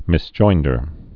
(mĭs-joindər)